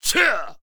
文件 文件历史 文件用途 全域文件用途 Enjo_atk_01_1.ogg （Ogg Vorbis声音文件，长度0.6秒，144 kbps，文件大小：11 KB） 源地址:地下城与勇士游戏语音 文件历史 点击某个日期/时间查看对应时刻的文件。